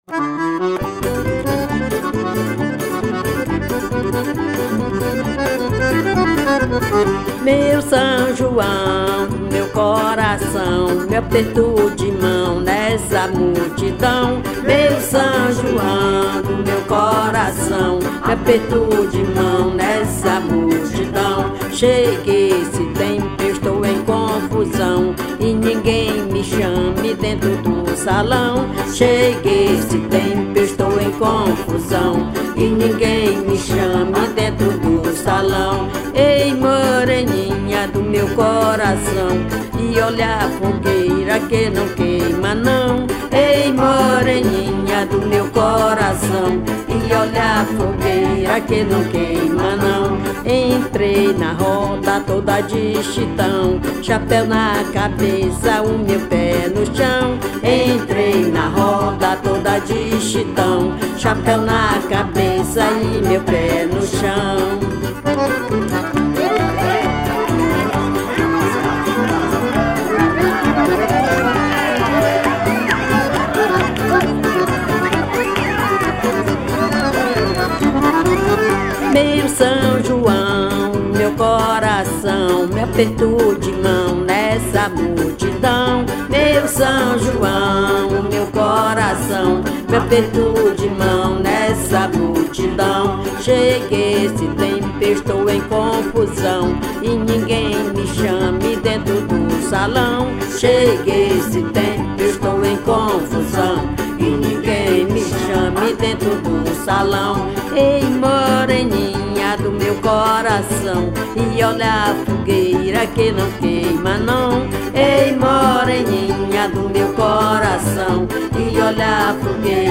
1250   02:35:00   Faixa:     Marcha